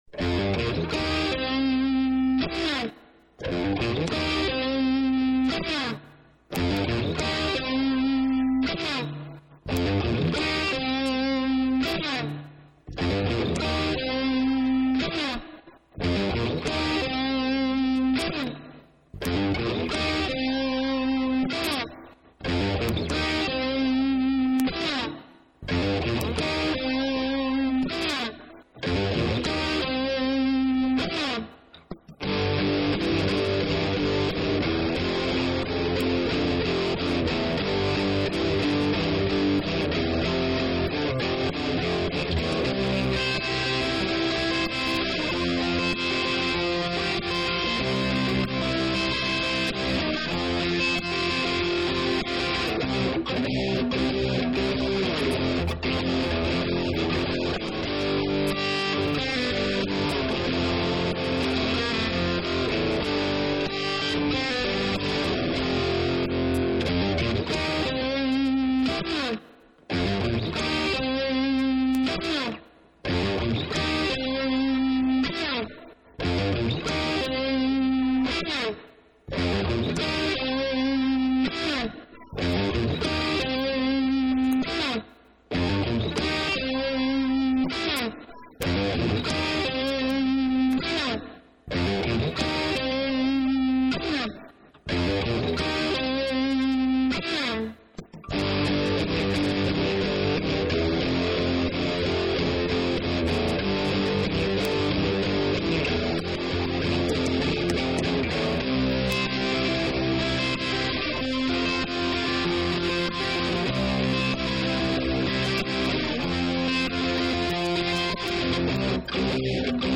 2.99MB 3:15 demo